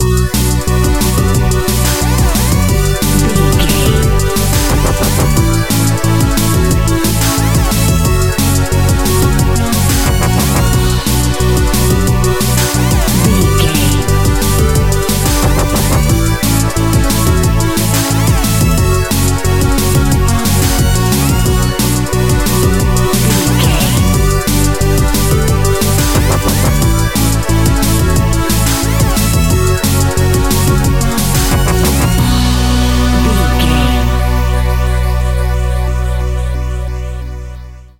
Fast paced
Aeolian/Minor
aggressive
dark
driving
energetic
drum machine
synthesiser
sub bass
synth leads
synth bass